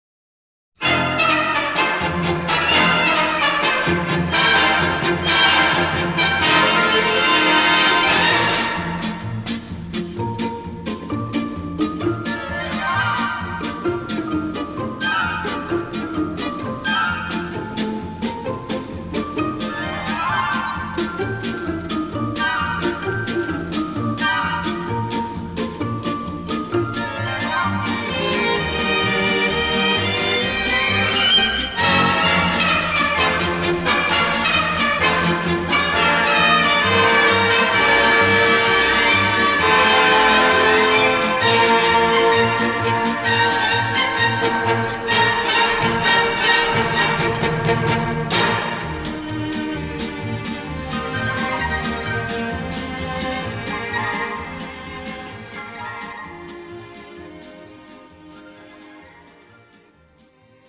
MR 고음질 반주 다운로드